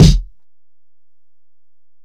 Kick (20).wav